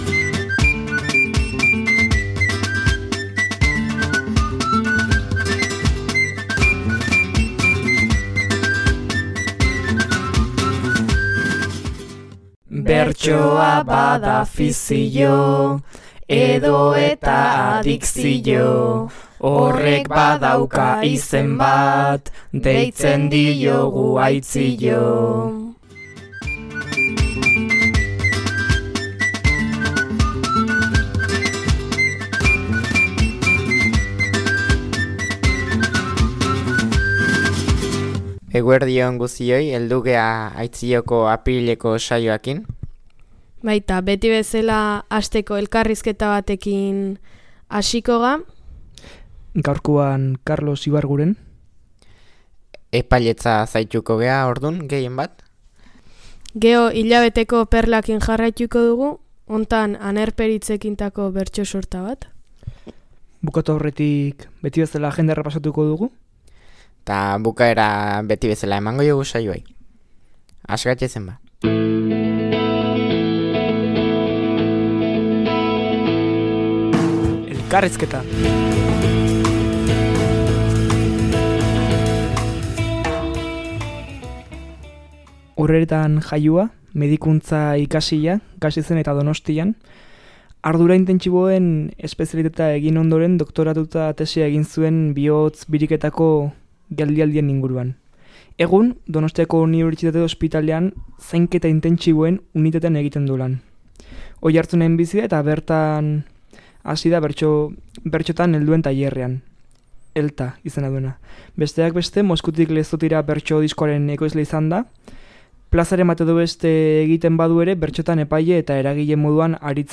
Ohi bezala agenda ere izan dute mintzagai eta saioaren laburpena, puntuka egin dute gazteek.